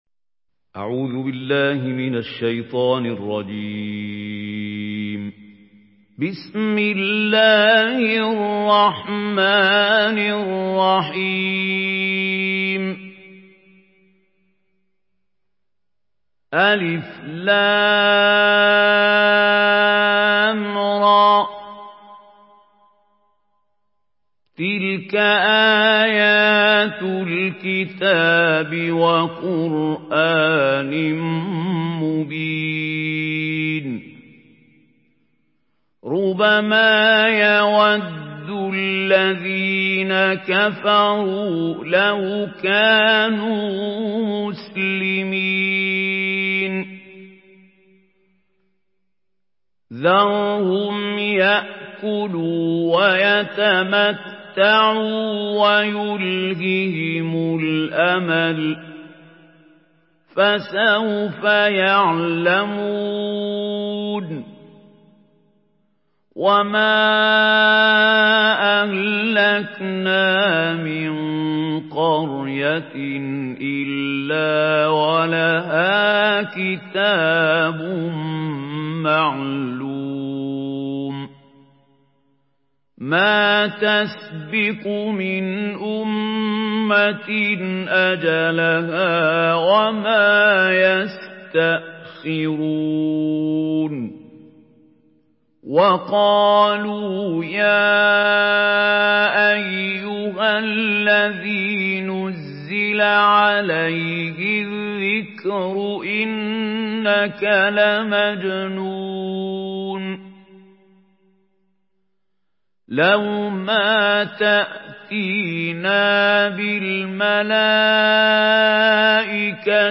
سورة الحجر MP3 بصوت محمود خليل الحصري برواية حفص
مرتل